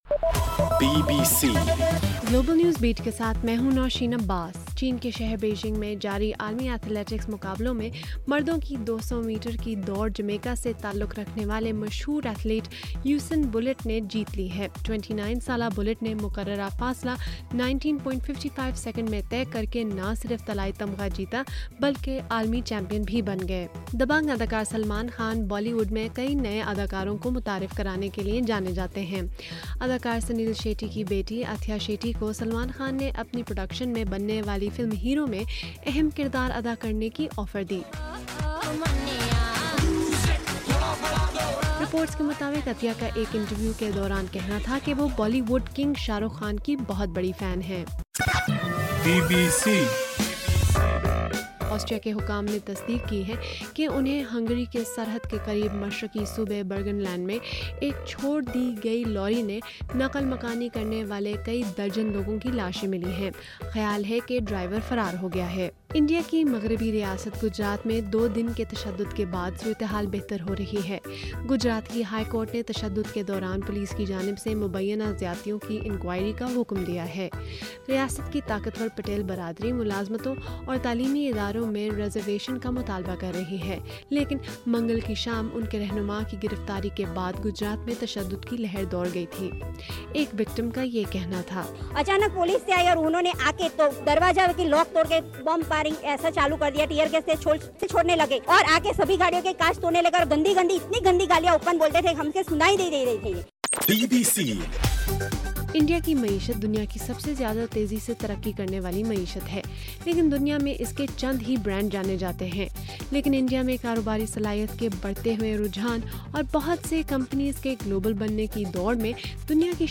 اگست 27: رات 9 بجے کا گلوبل نیوز بیٹ بُلیٹن